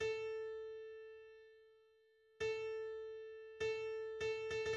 Nota bakoitzak har ditzakeen iraupenak: